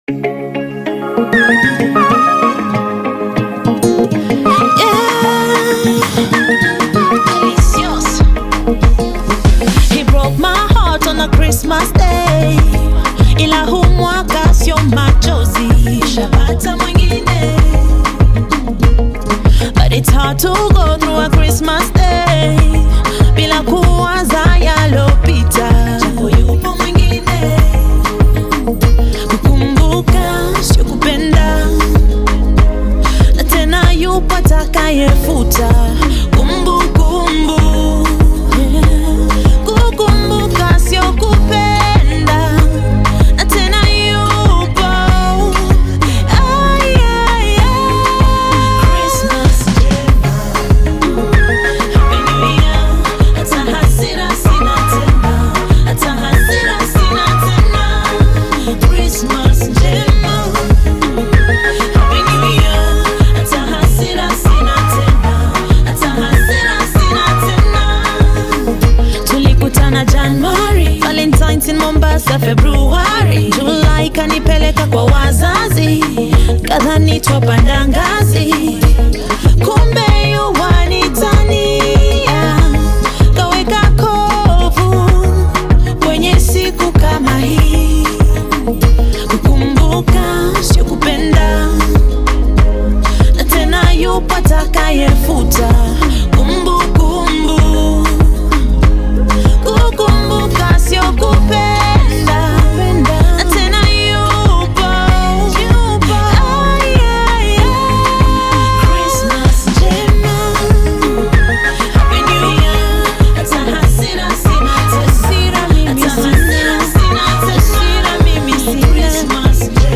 Christmas love song